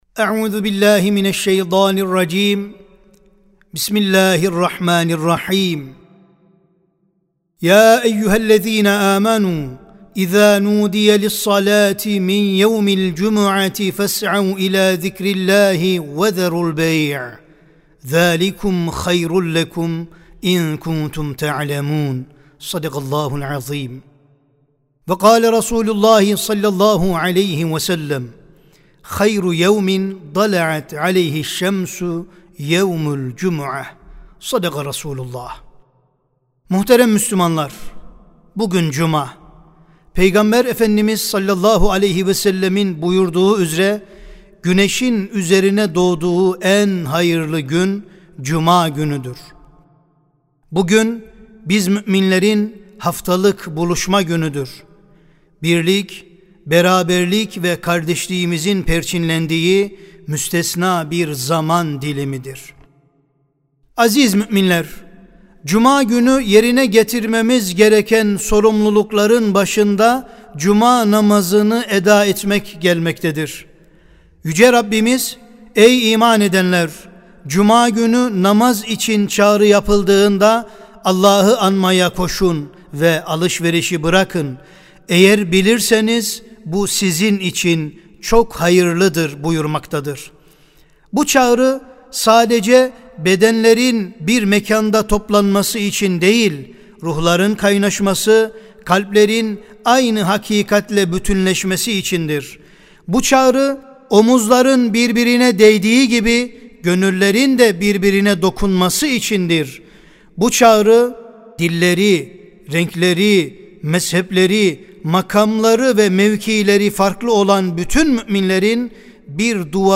Sesli Hutbe (Cuma ve Ümmet Bilinci).mp3